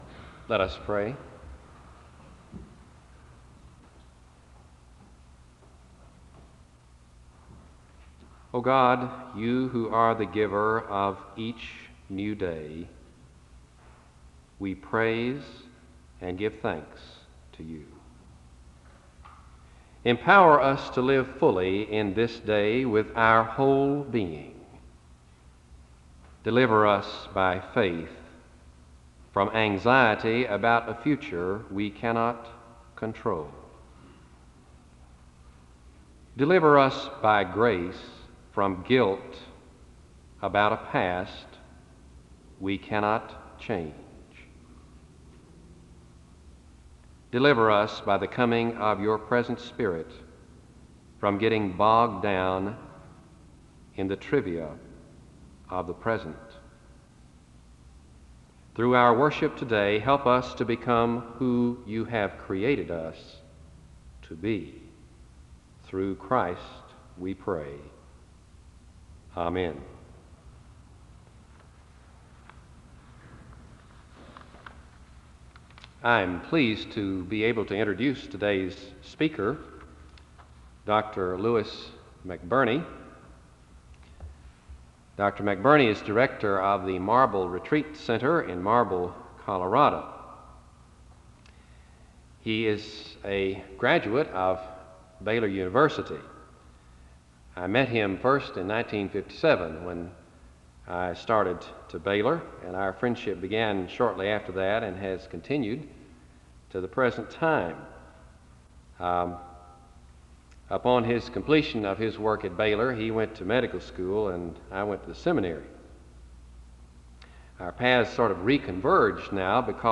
CO. The service opens with a prayer (00:00-01:11).
SEBTS Chapel and Special Event Recordings